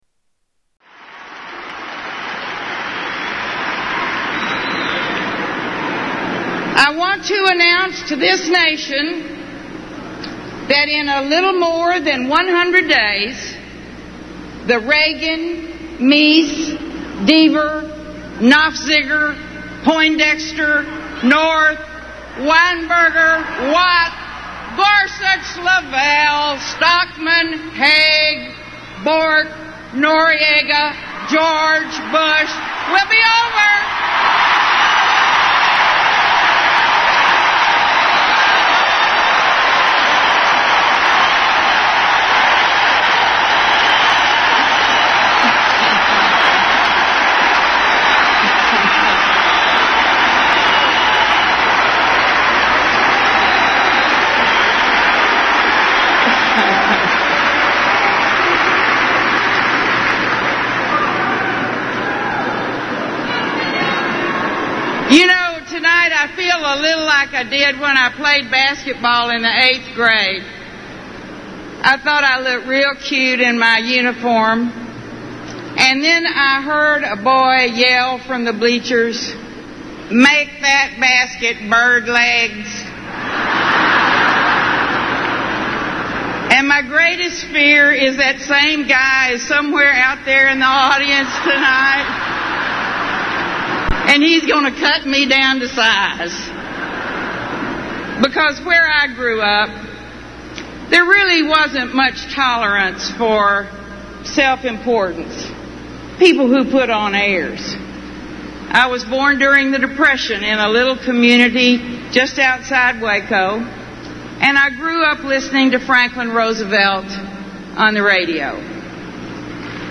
Keynote Address 2